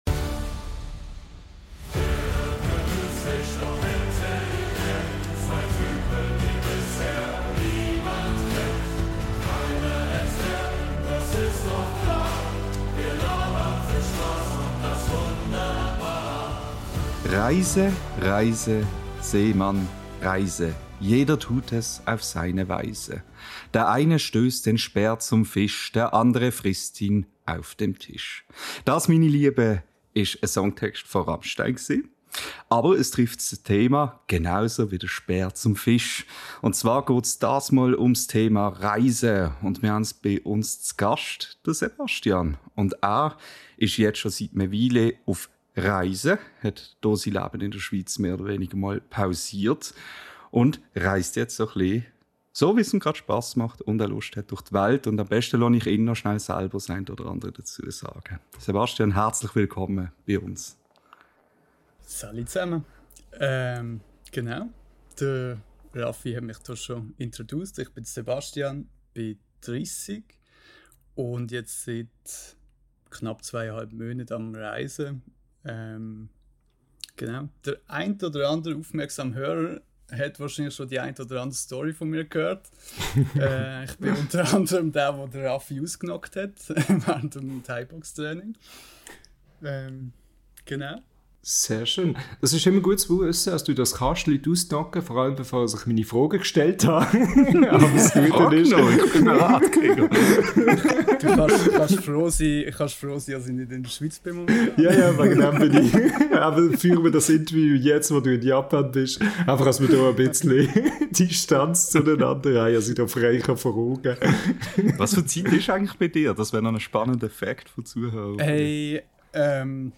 live zugeschaltet aus Japan